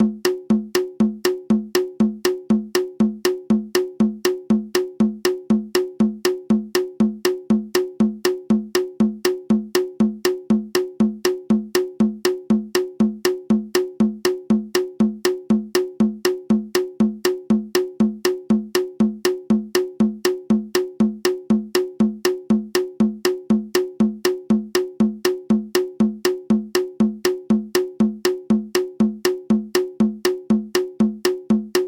• le plus petit : le cachimbo, tumbador, tumba, tercero, repicador ou golpe.
La Yuka peut se jouer en 2/4 ou en 6/8. Le rythme est interprété à tempo modéré.
Voici un exemple de Yuka dans lequel les musiciens qui jouent la mula et le cachimbo percutent le fût avec une baguette (croix en haut de la portée) et jouent sur la peau du tambour avec l'autre main :
Rythme de la Yuka